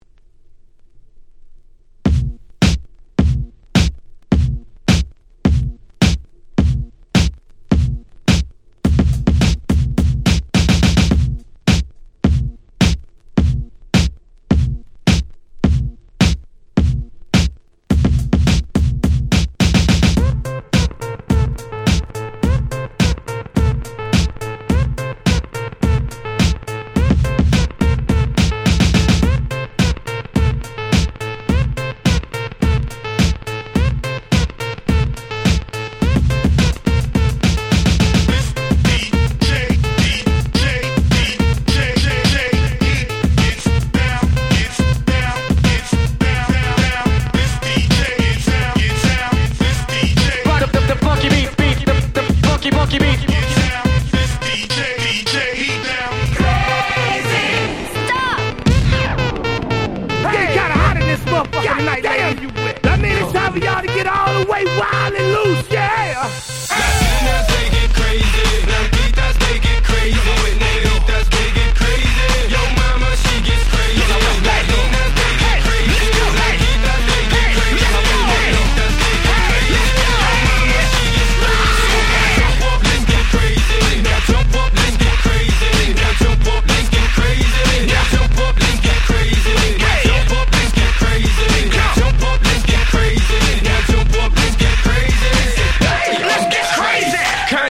DJがPlay中にBPMを変えるのに超便利なトランジション物を全6曲収録しためちゃ使える1枚！！
(106BPM-125BPM)